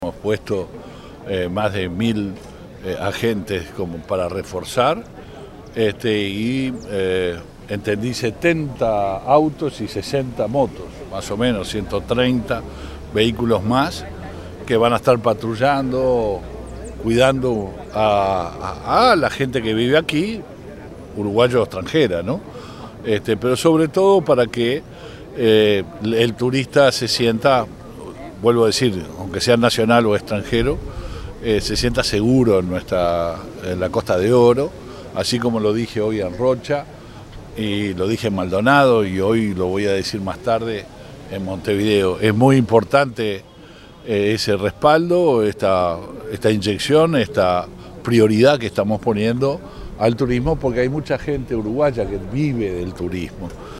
luis_alberto_heber_ministro_del_interior.mp3